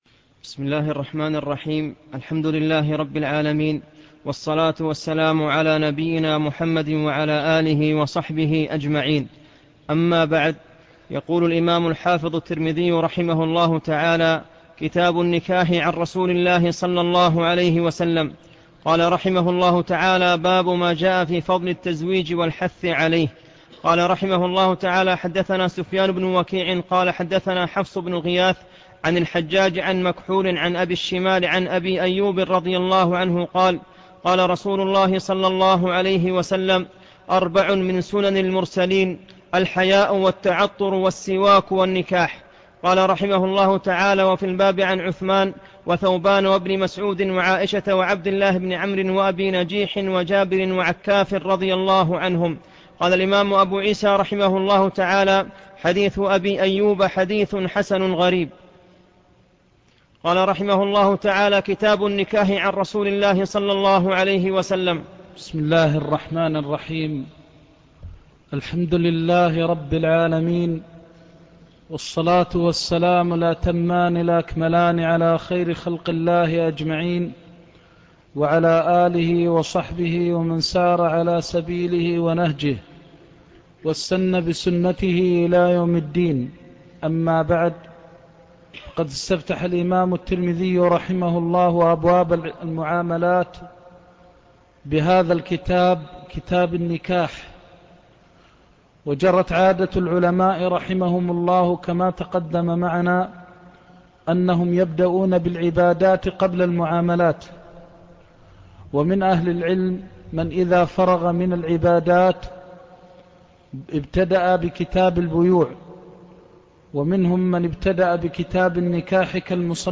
الدرس الأول - الشيخ محمد محمد المختار الشنقيطي